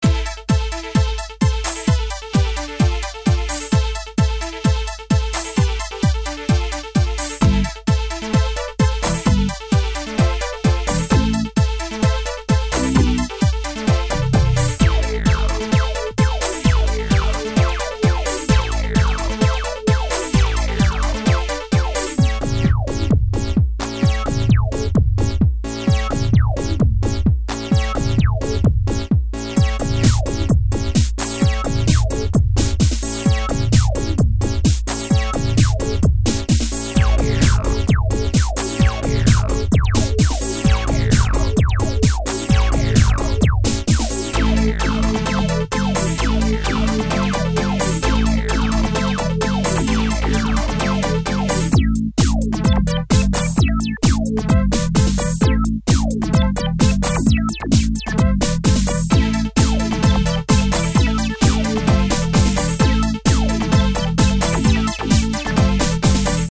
moogdemo.mp3